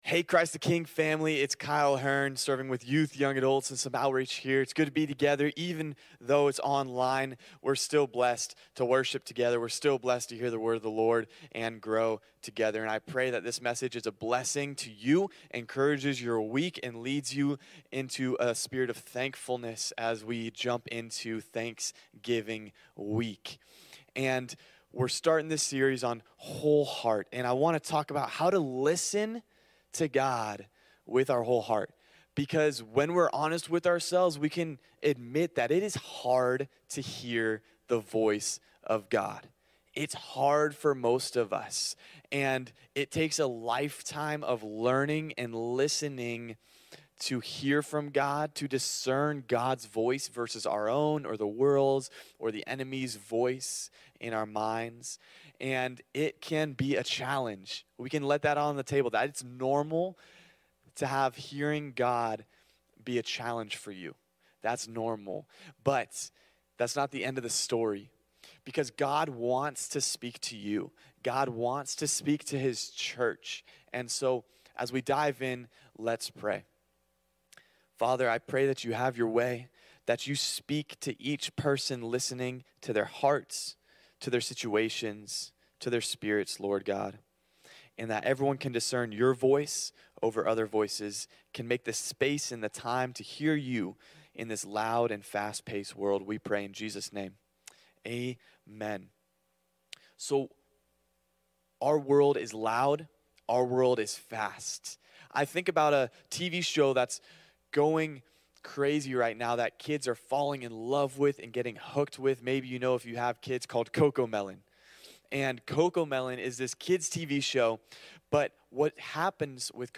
CTK-Clipped-Sermon-Oct-20-.mp3